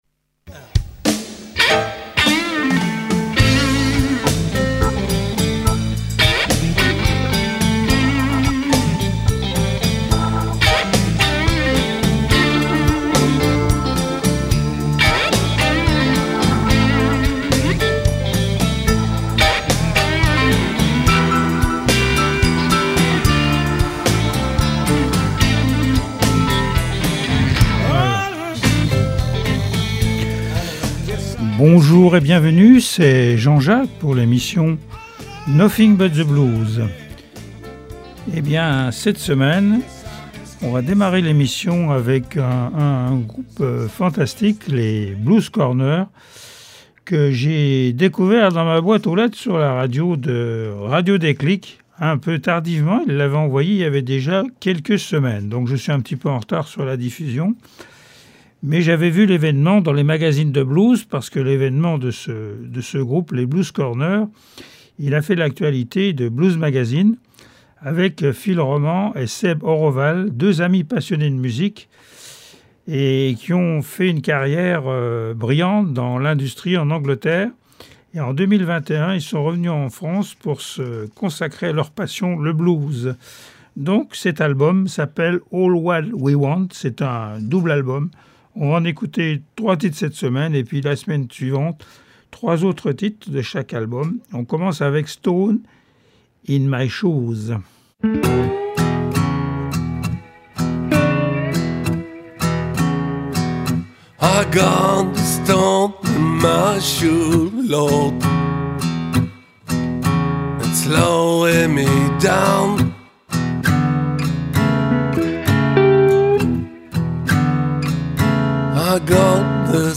Du traditionnel au blues rock actuel.
L’émission offre un espace aux musiciens Lorrains et à la particularité de présenter de la musique en Live et des interviews.